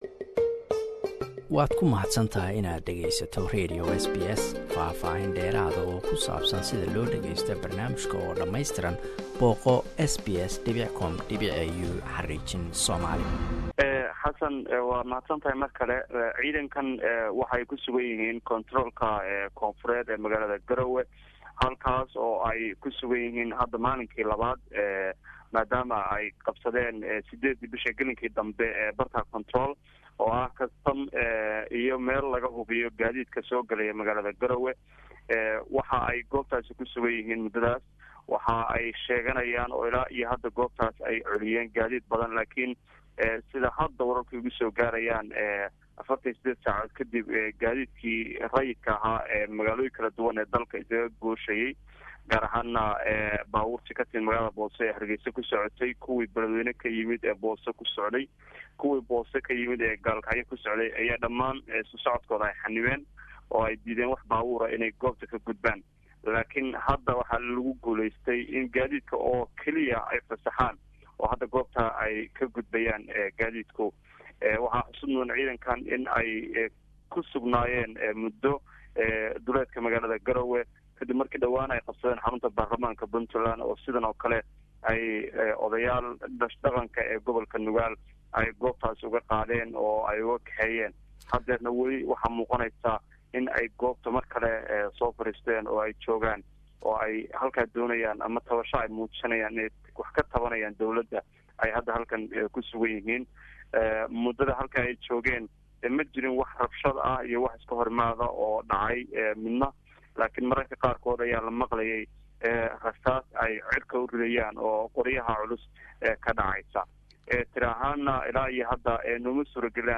Askar ka tirsan Puntland ayaa qabsatay kastamka magaalada Garowe iyadoo ka cabanay mushaarooyin aan la siin. Dawlada ayaa dafirtay inaysan ciidanka mushaarkooda siin. Waxaan dhacdadan u danbaysay ka waraysanay wariye ku sugan Garowe.